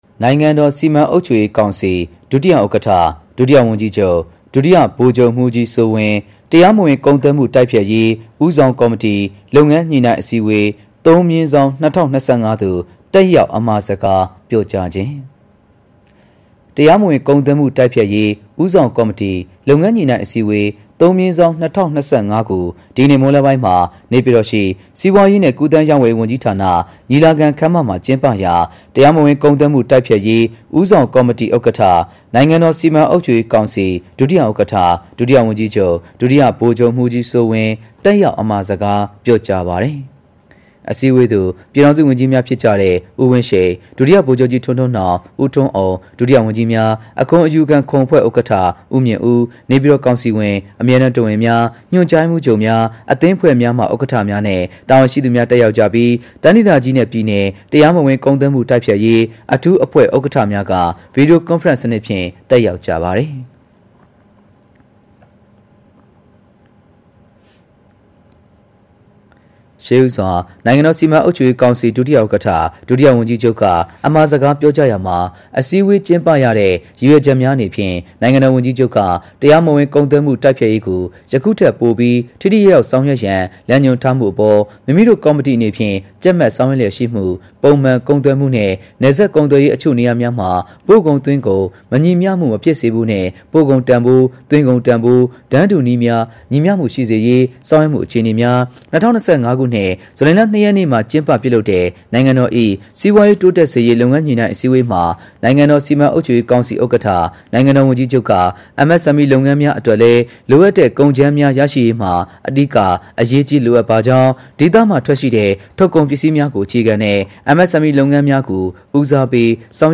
နိုင်ငံတော်စီမံအုပ်ချုပ်ရေးကောင်စီ ဒုတိယဥက္ကဋ္ဌ၊ ဒုတိယဝန်ကြီးချုပ် ဒုတိယဗိုလ်ချုပ်မှူးကြီးစိုးဝင်း တရားမဝင်ကုန်သွယ်မှု တိုက်ဖျက်ရေး ဦးဆောင်ကော်မတီ လုပ်ငန်းညှိနှိုင်းအစည်းအဝေး (၃/၂၀၂၅)သို တက်ရောက် အမှာစကားပြောကြား